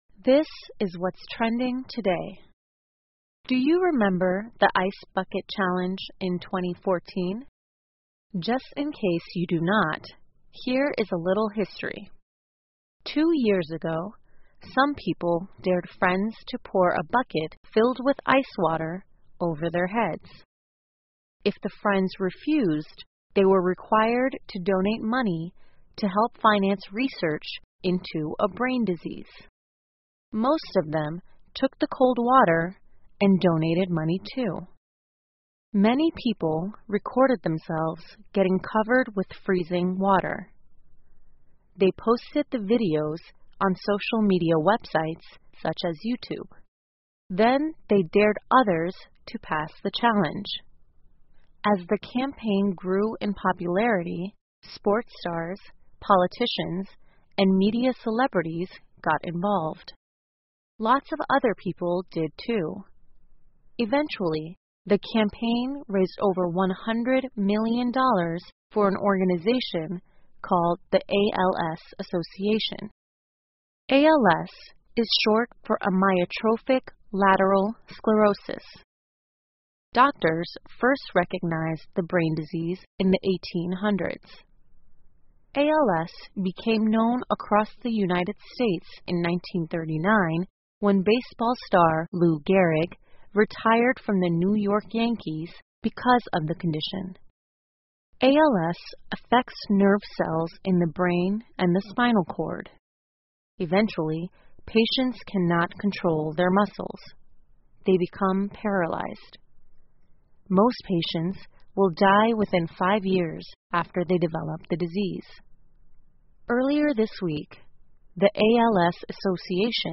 VOA慢速英语--冰桶挑战捐款帮助发现致病基因 听力文件下载—在线英语听力室